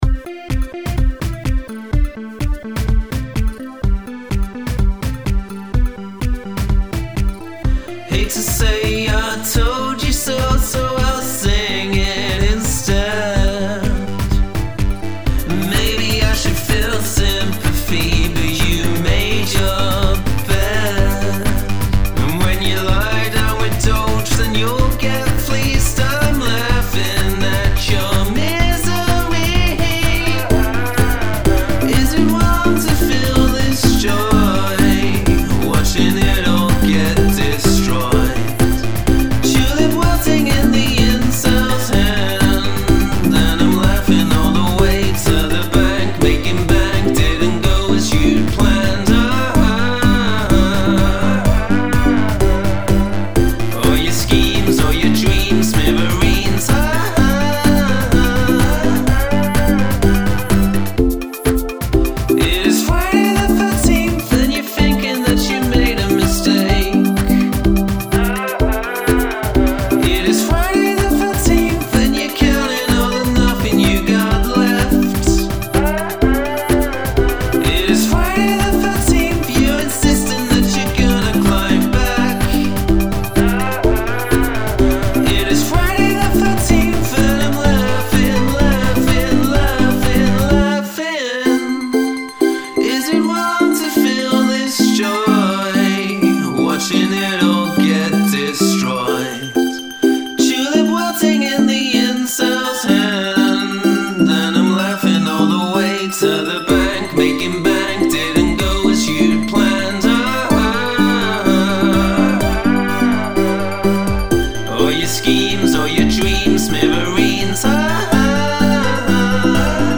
write a feel-good song with happy lyrics and upbeat music
Love those tinkly synths.